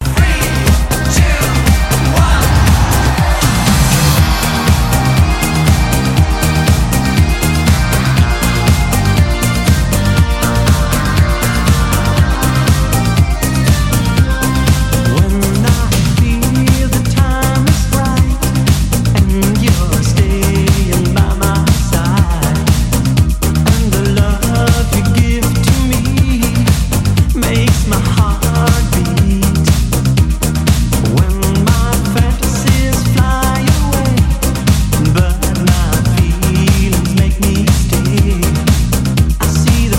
synth pop , диско
танцевальные